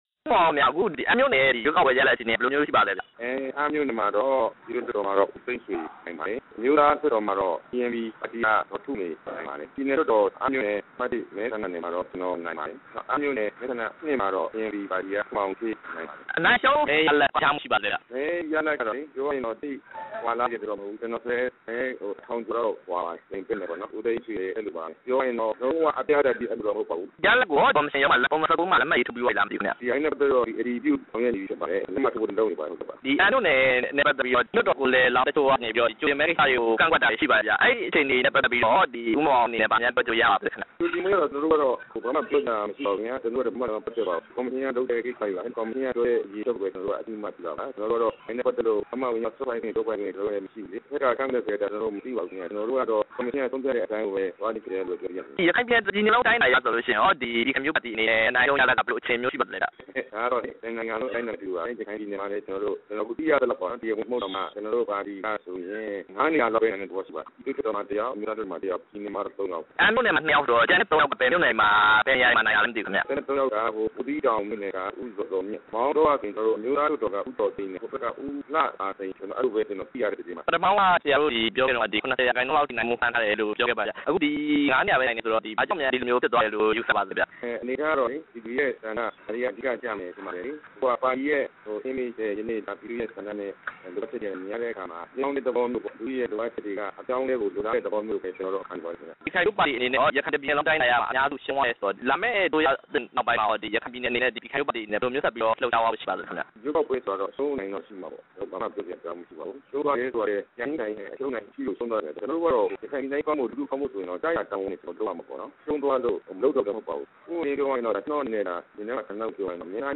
ရခိုင်ပြည်နယ် အမ်းမြို့နယ် ပြည်နယ်လွှတ်တော်မဲဆန္ဒနယ်အမှတ် ၁မှာ ဝင်ရောက်ယှဉ်ပြိုင်ပြီး အနိုင်ရရှိခဲ့တဲ့ ကြံ့ခိုင် ဖံ့ွဖြိုးရေးပါတီ ကိုယ်စားလှယ် ဦးမောင်မောင်အုန်းက RFA နဲ့ သီးသန့်ဆက်သွယ်မေးမြန်းခန်းမှာ လက်ရှိရွေးကောက်ပွဲ ရလဒ်တွေအပေါ် သဘောထားကို ပြောကြားခဲ့တာဖြစ်ပါတယ်။